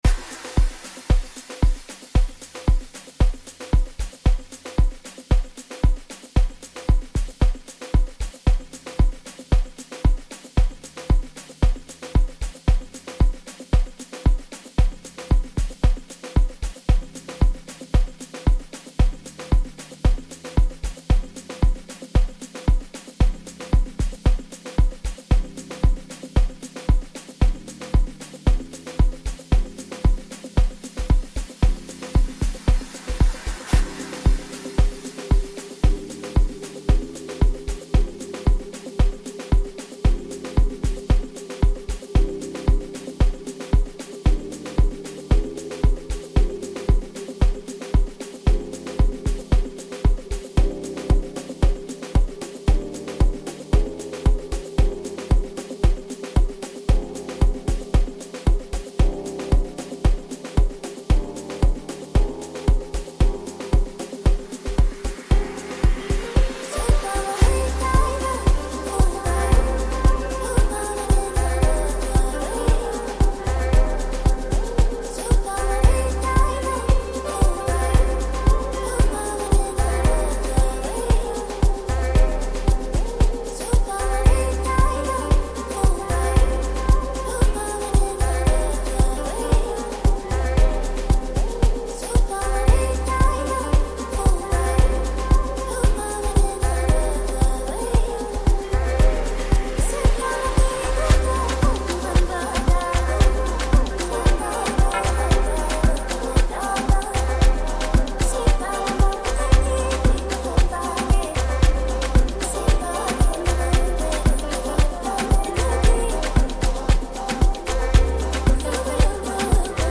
Amapiano/Hip Hop/House